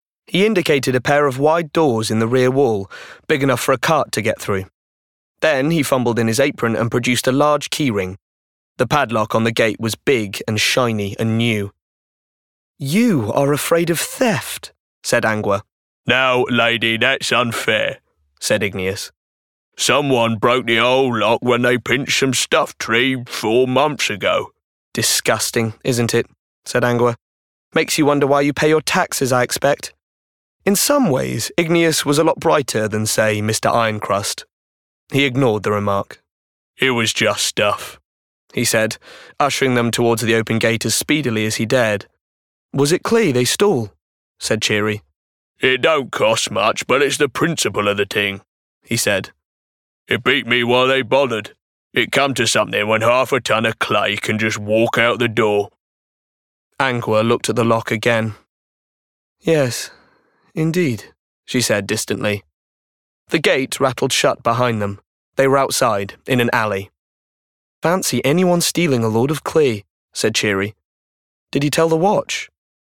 20s-30s. Male. Derbyshire.